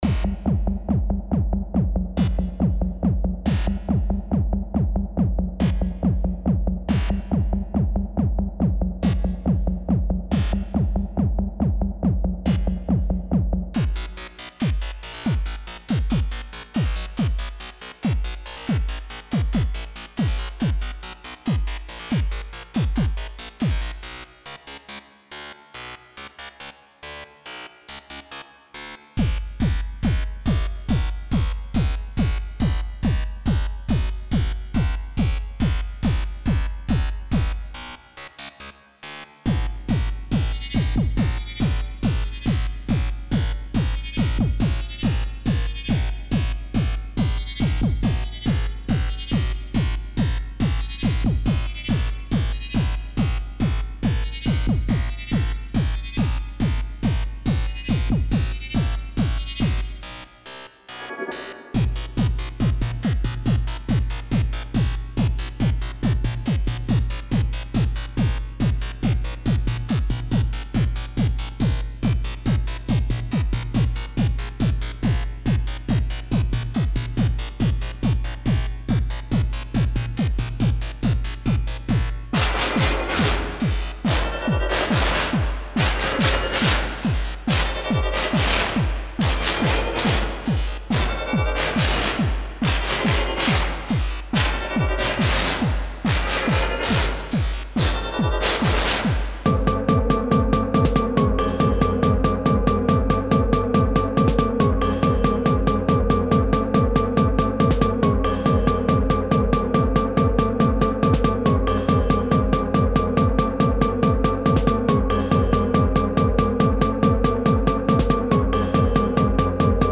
Psy-bass